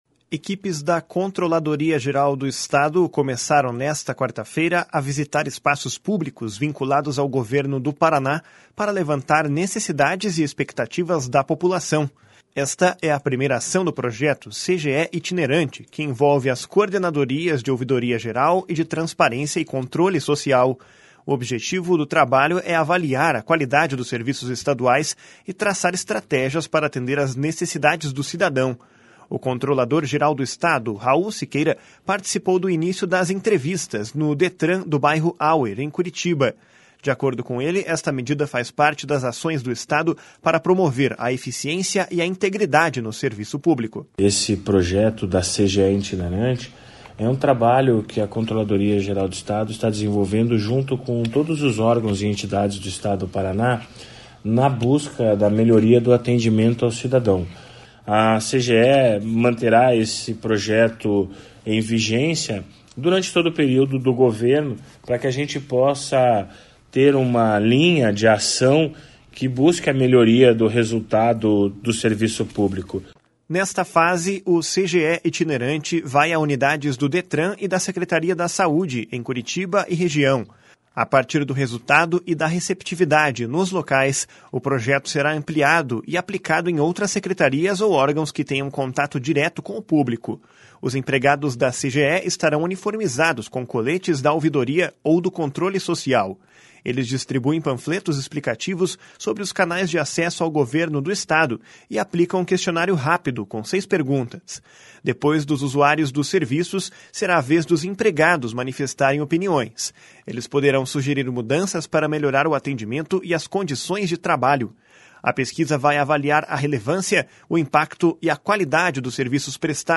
O objetivo do trabalho é avaliar a qualidade dos serviços estaduais e traçar estratégias para atender às necessidades do cidadão. O controlador-geral do Estado, Raul Siqueira, participou do início das entrevistas, no Detran do Hauer, em Curitiba.
// SONORA RAUL SIQUEIRA //